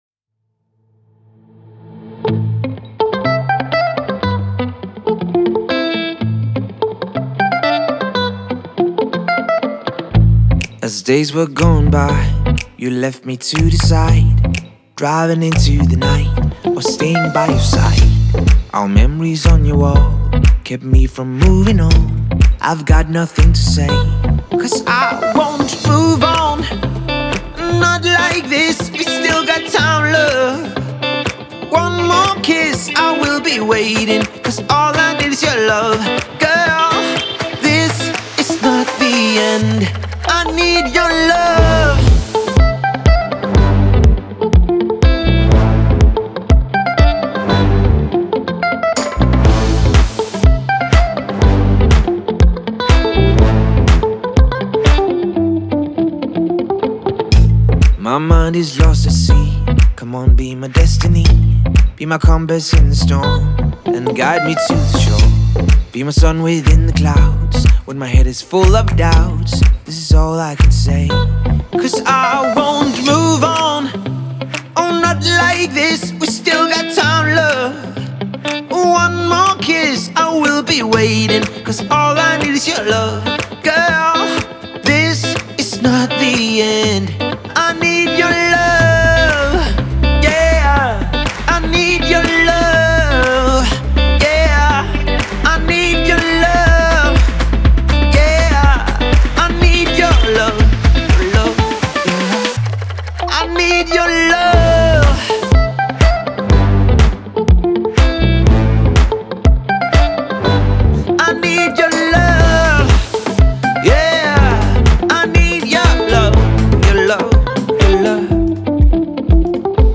это энергичная танцевальная композиция в жанре EDM
демонстрирует мощный вокал